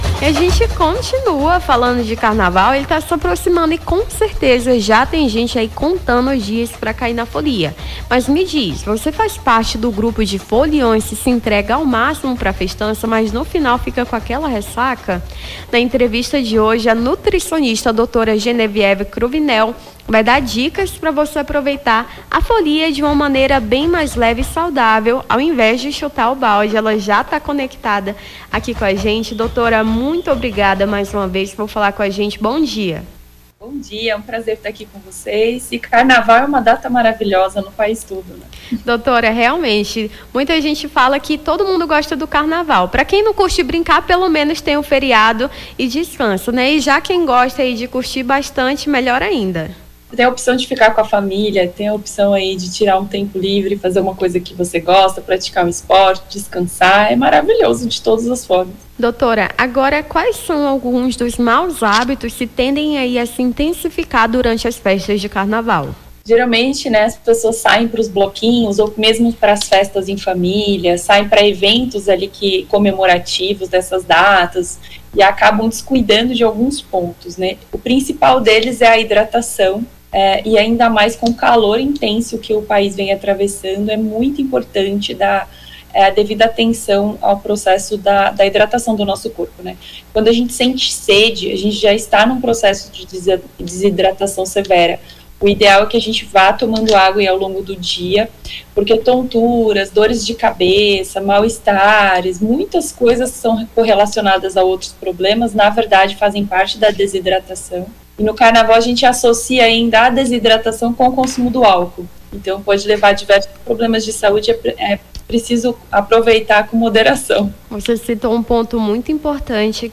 Nome do Artista - CENSURA - ENTREVISTA CUIDADOS CARNAVAL (19-02-25).mp3